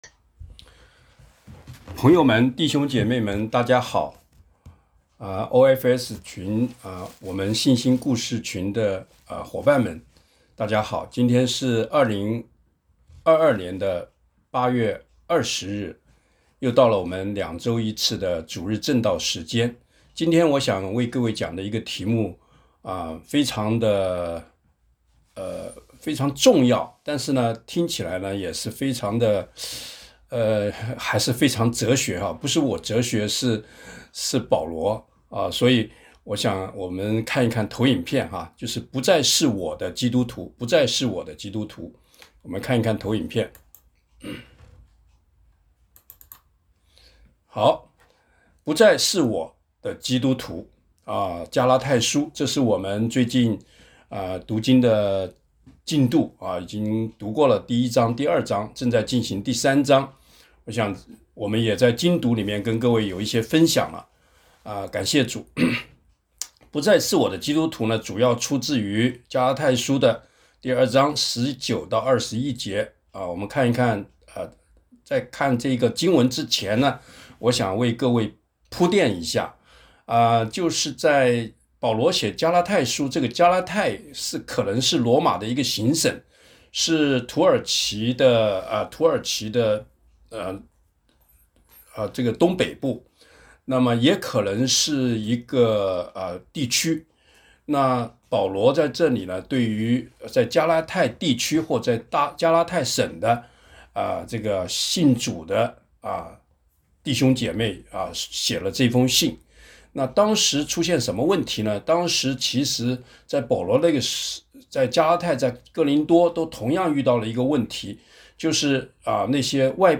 今天是每两周1次的主日时间。